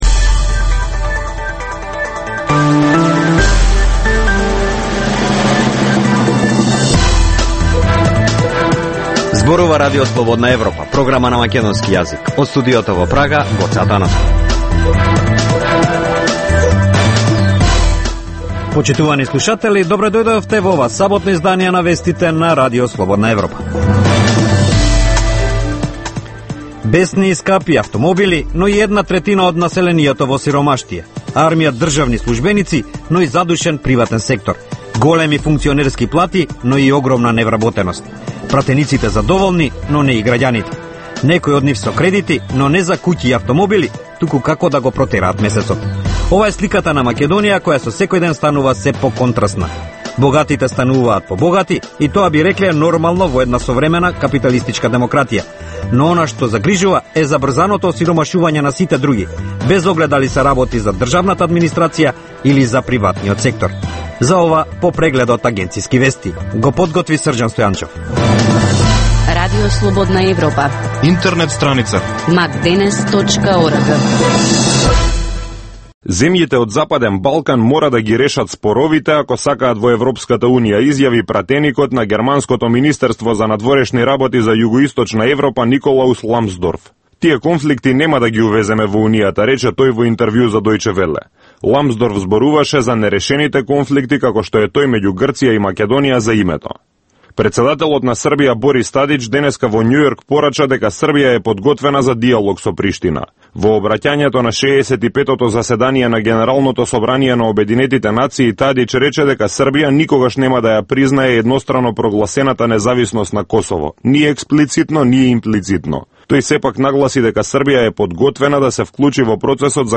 Преглед на вестите и актуелностите од Македонија и светот, како и локални теми од земјата од студиото во Прага.